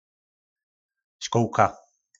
Slovník nářečí Po našimu
Školka - Škółka